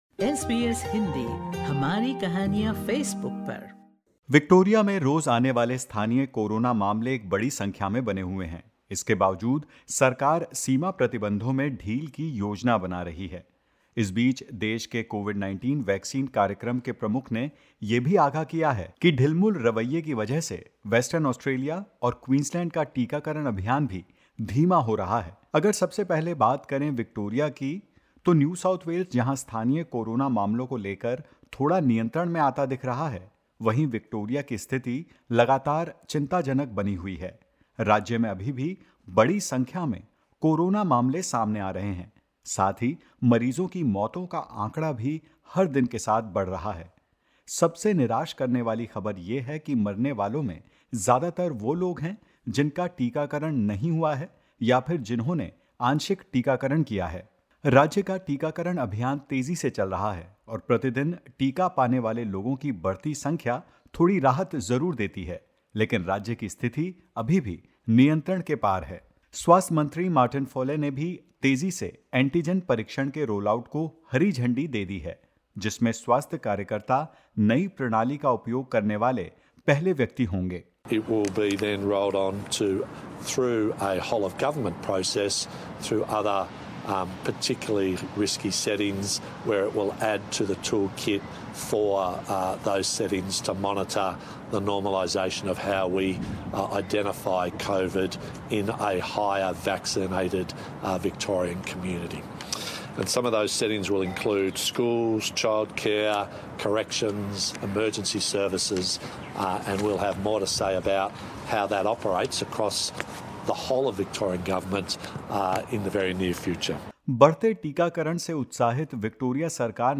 बढ़ते कोविड -19 मामलों के बावजूद विक्टोरिया ने कुछ सीमा प्रतिबंधों में बदलाव किया है। क्या हैं यह बदलाव और कोरोना को लेकर क्या कुछ चल रहा है ऑस्ट्रेलिया में, सुनिए इस रिपोर्ट में।